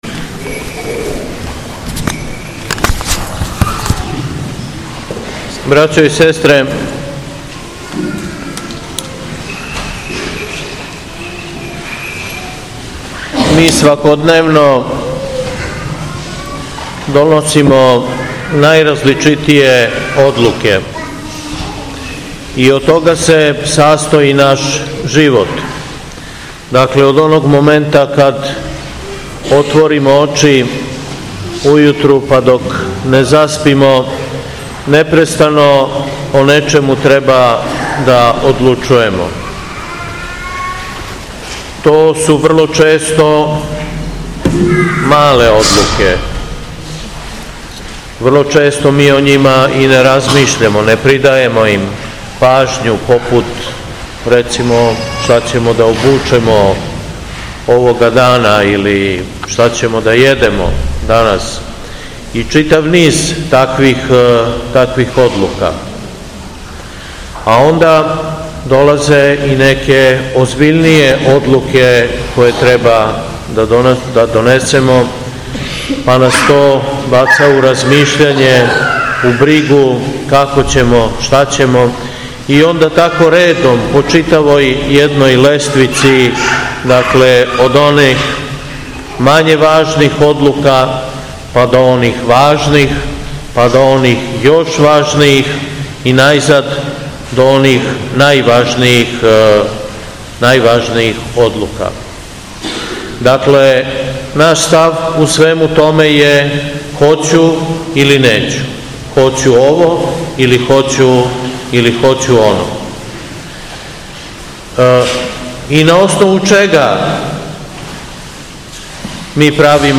СВЕТА АРХИЈЕРЕЈСКА ЛИТУРГИЈА НА ДЕТИЊЦЕ У ХРАМУ СВЕТОГА САВЕ НА АЕРОДРОМУ - Епархија Шумадијска
Беседа